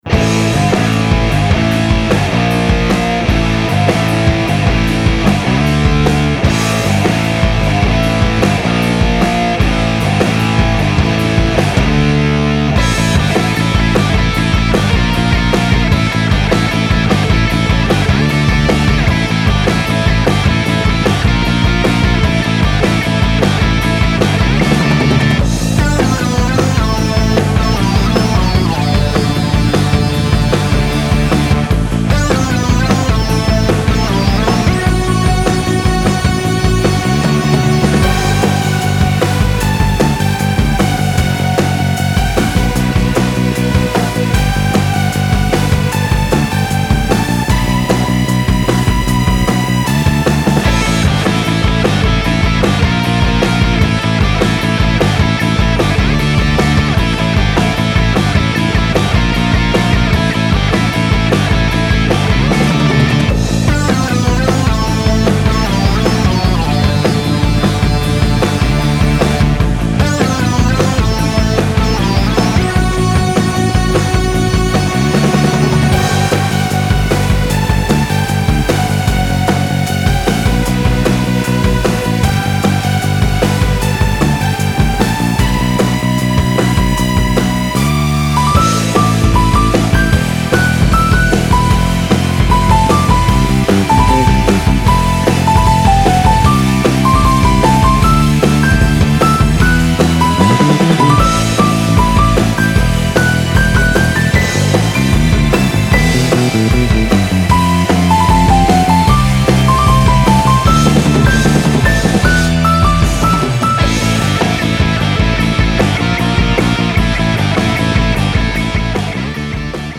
フリーBGM バトル・戦闘 バンドサウンド
フェードアウト版のmp3を、こちらのページにて無料で配布しています。